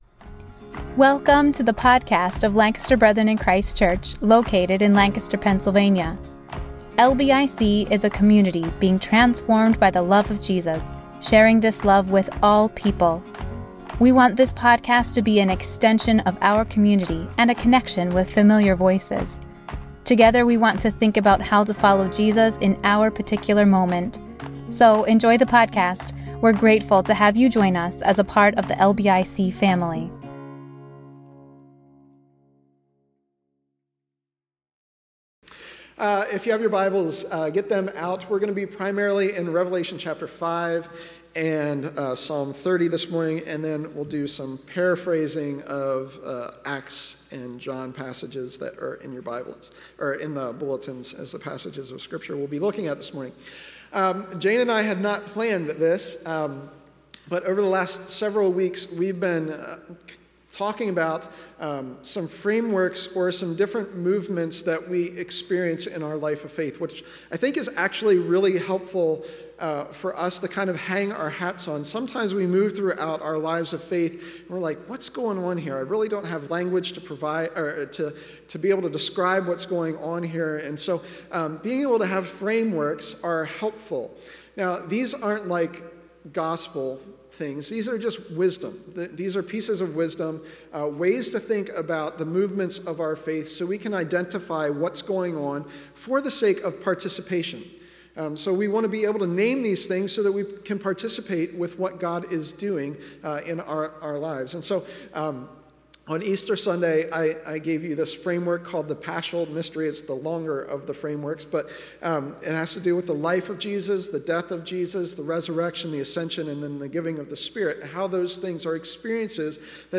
A message from the series "Eastertide."